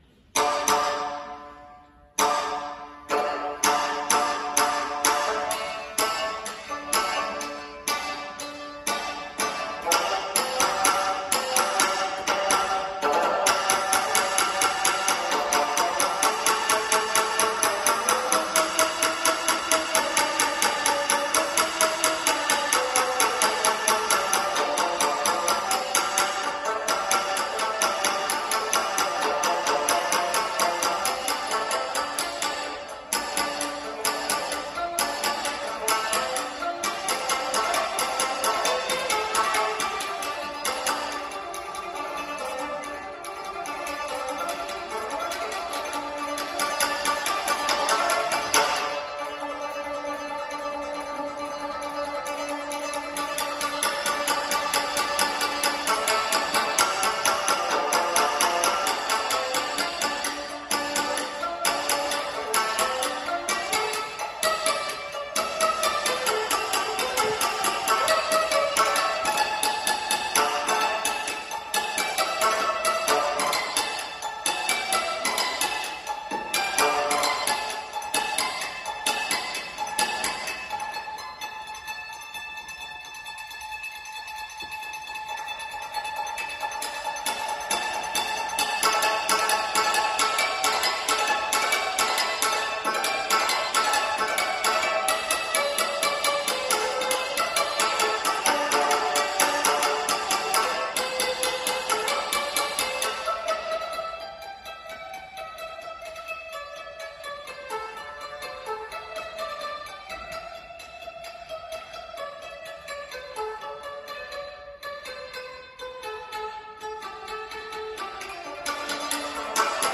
新節はその中の１つで、特徴はスピード感のあるテンポと複雑なフレーズが多いことです。
曲弾き
曲弾きとは、主に津軽五大民謡を演奏する際に見られる演奏スタイルの１種で、唄や太鼓と合わせるのではなく、津軽三味線１本だけで演奏するというスタイルです。
以下の音源が昨年の大会本番での演奏です。
私は本番で自分の前の奏者のテンポにつられてしまい、練習していたときよりもかなり速いテンポで演奏してしまいました。
その結果、複雑なフレーズの部分で指が追い付かず、細かい音が聴きとりづらくなってしまいました。